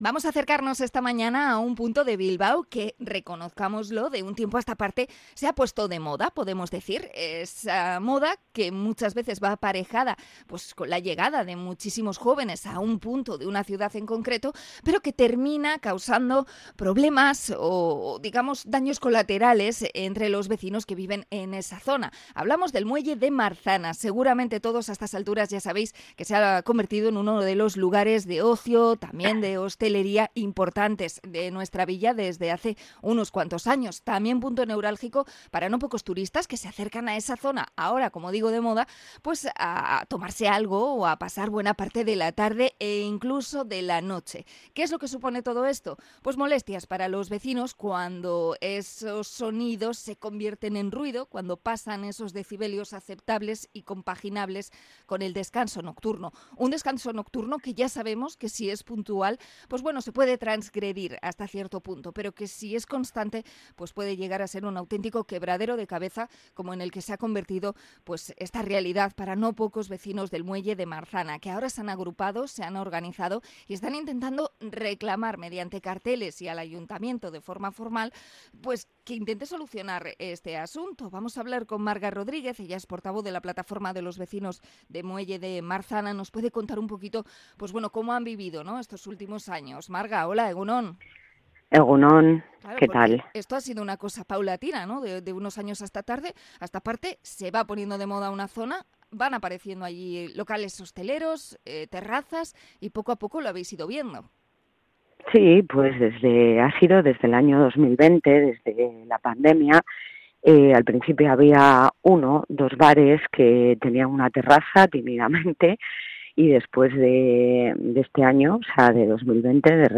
Entrevista a los vecinos de Marzana por el ruido